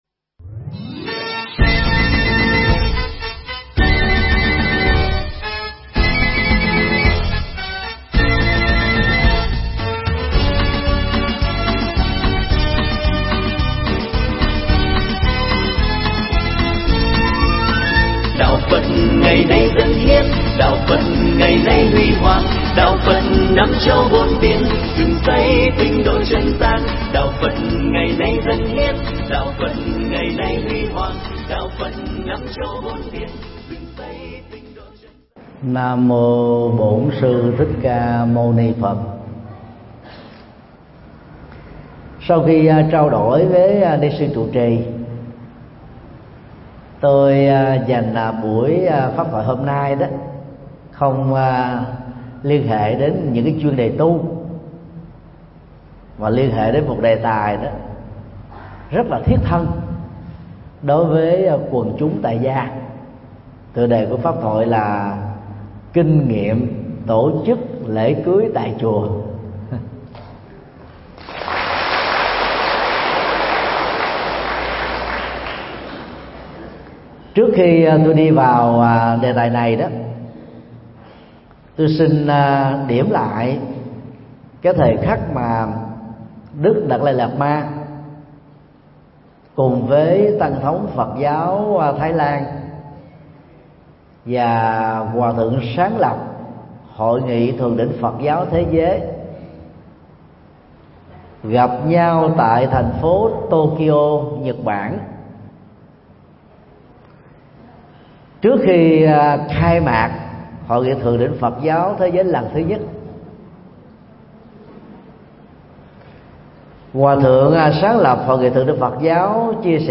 Mp3 Pháp Thoại Kinh Nghiệm Tổ Chức Lễ Cưới Tại Chùa
giảng tại chùa Vẽ (Hải Phòng)